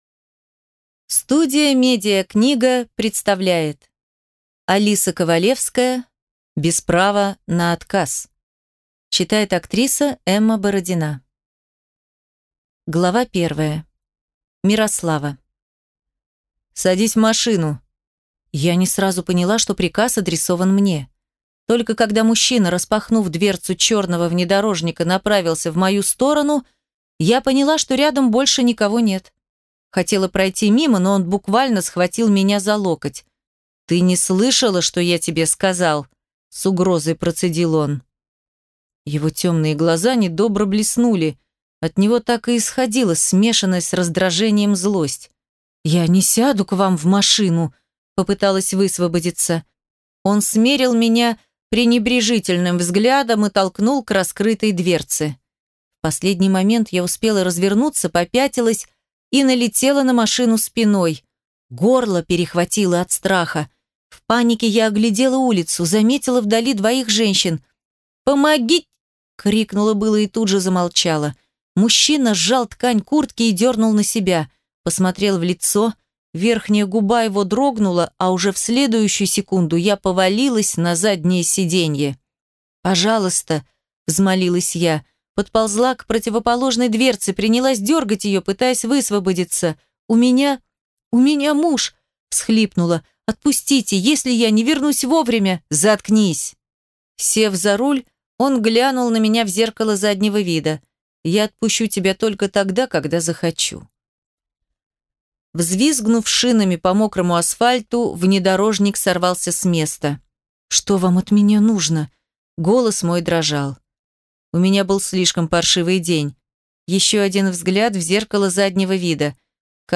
Аудиокнига Без права на отказ | Библиотека аудиокниг